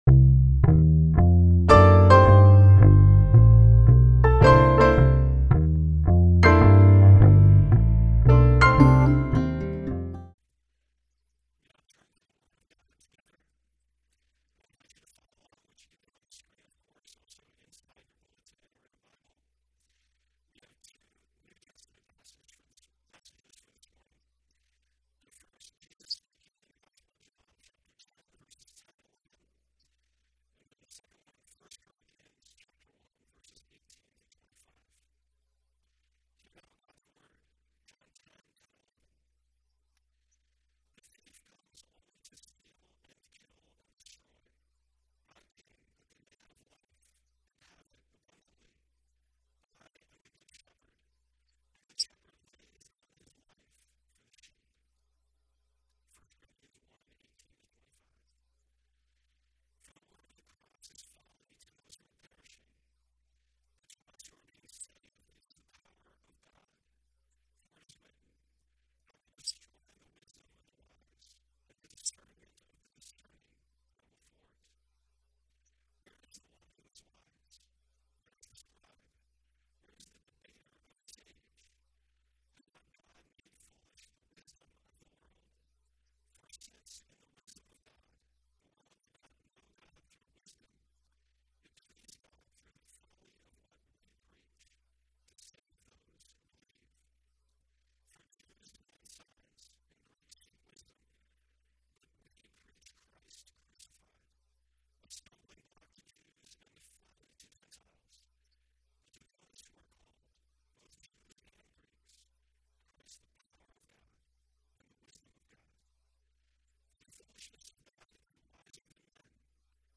Sermon
Service Type: Sunday Worship